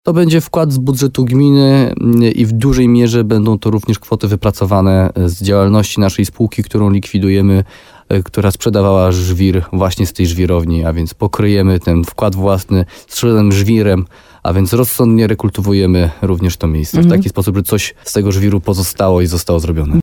– Szacunkowo potrzeba jeszcze około 4 milinów złotych, żeby udało się zrealizować te atrakcje turystyczną – mówi wójt Jarosław Baziak.